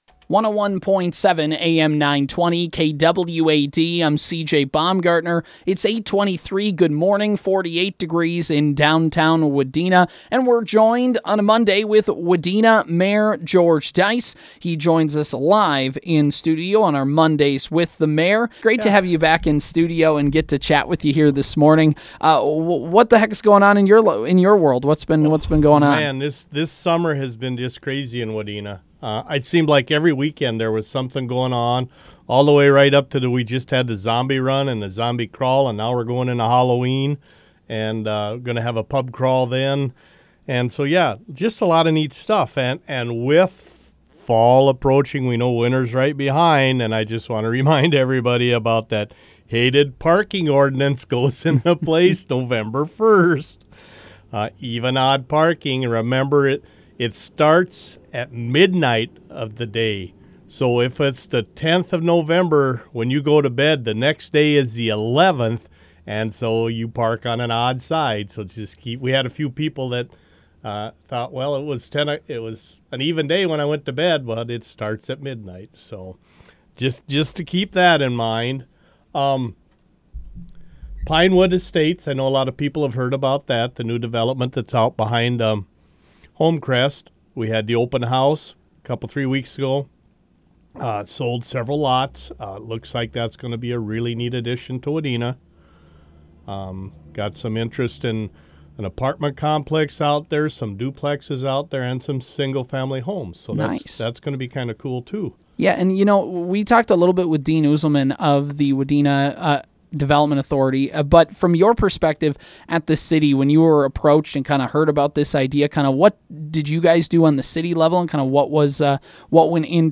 Wadena Mayor George Deiss stopped in studio to give an update on the City of Wadena and other city announcements.
You can hear our conversation with Mayor Deiss below!